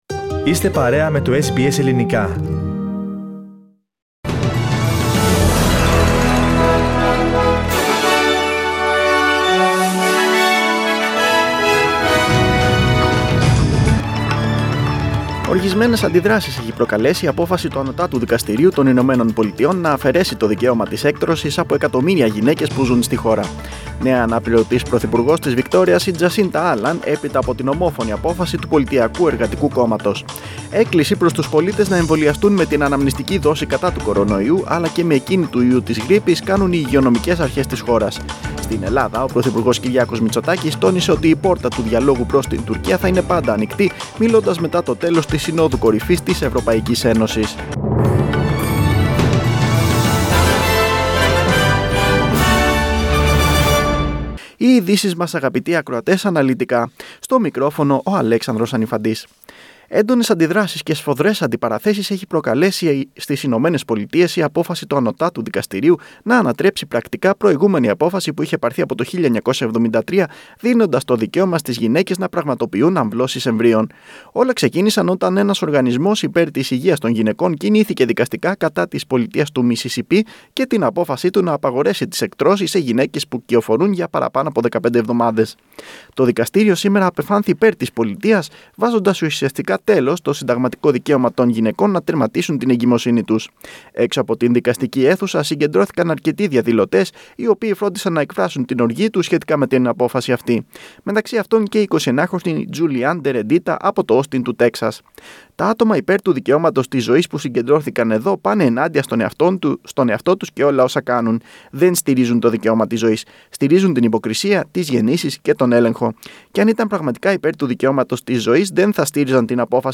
Δελτίο Ειδήσεων 25.6.2022
News in Greek. Source: SBS Radio